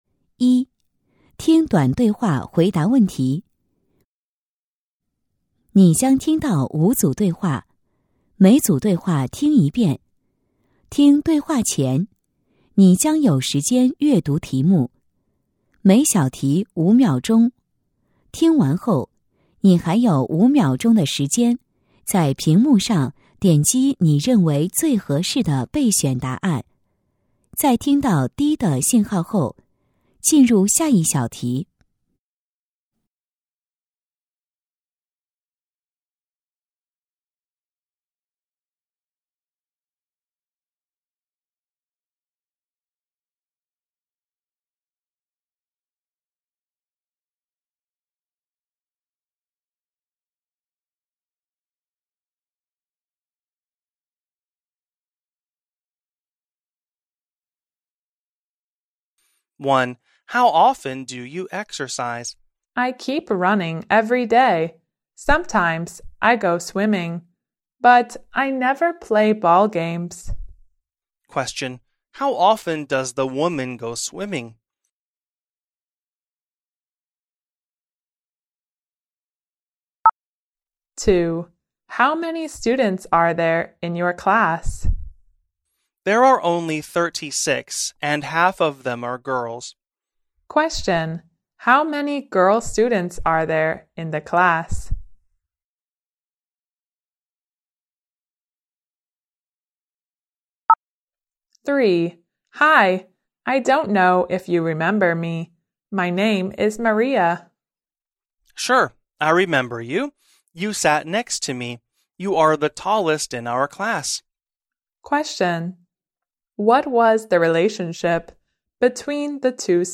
中考模拟试卷（七）·初中总复习配套测试卷（2022版淄博专用）-听力MP3